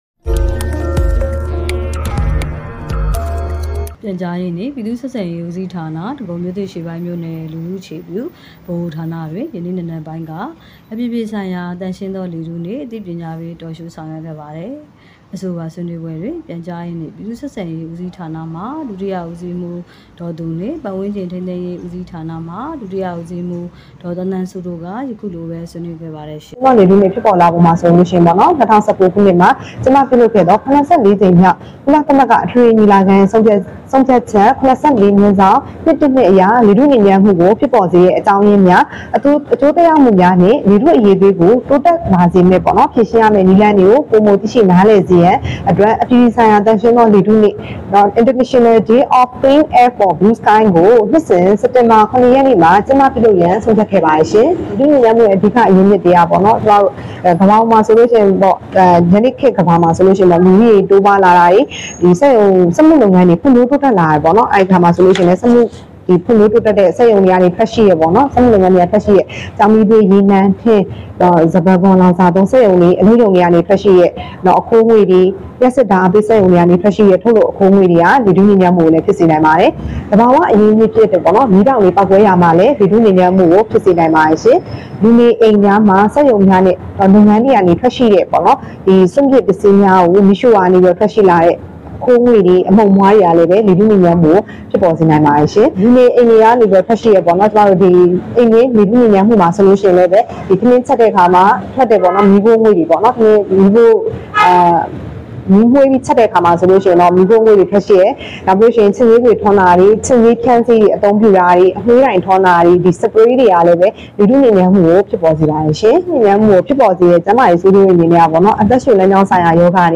ဒဂုံမြို့သစ်(မြောက်ပိုင်း)မြို့နယ်တွင် လူကုန်ကူးမှု အသိပညာပေး Talk Show...